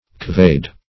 Couvade \Cou`vade"\ (k[=oo]`v[.a]d"), n. [F., fr. couver. See